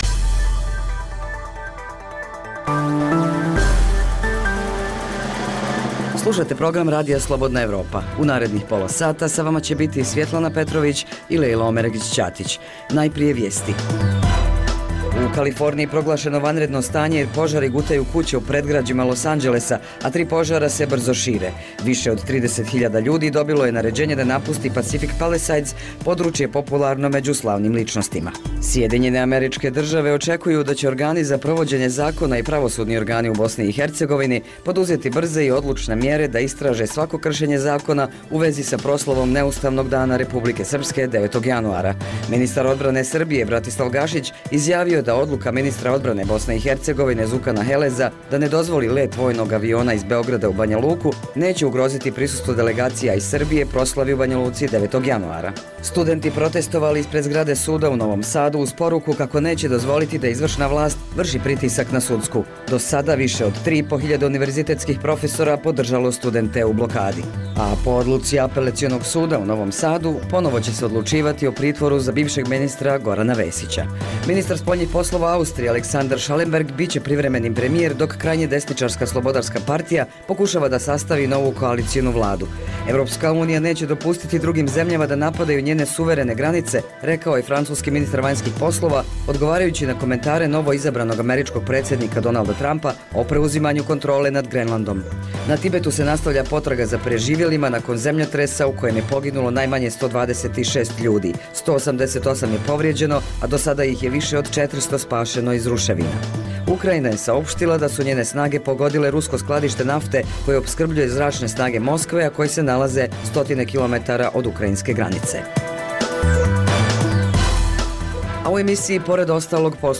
Dnevna informativna regionalna emisija Radija Slobodna Evropa o dešavanjima u zemljama Zapadnog Balkana i svijeta.